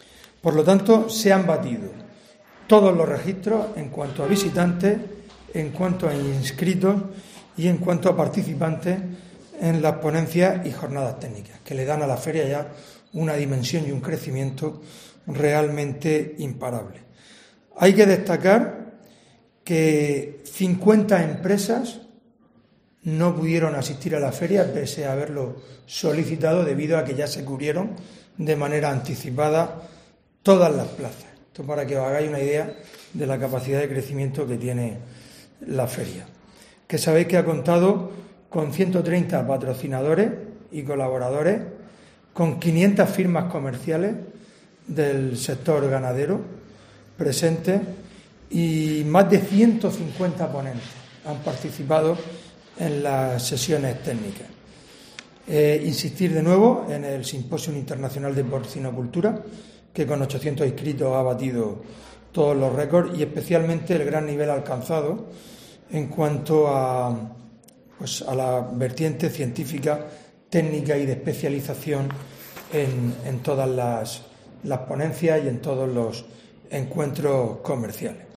Fulgencio Gil, alcalde de Lorca sobre SEPOR 2023